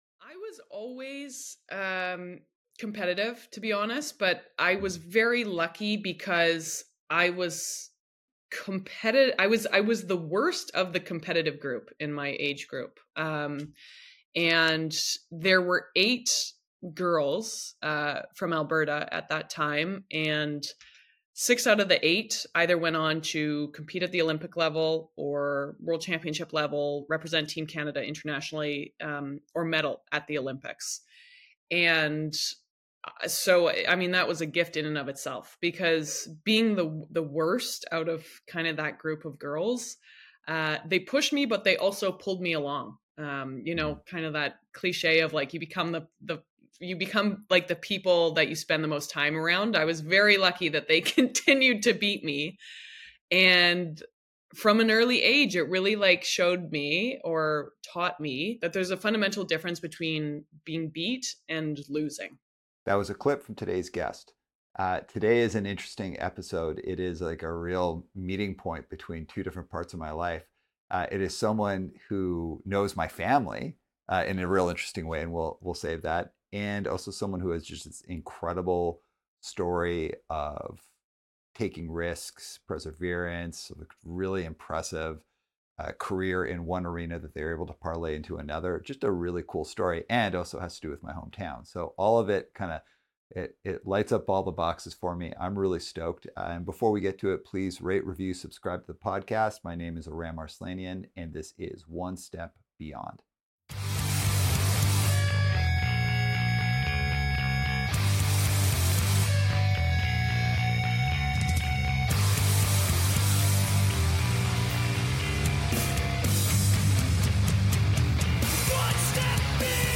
On this episode of One Step Beyond, we are joined by Anastasia Bucsis, two-time Olympic speed skater and host, CBC Sports.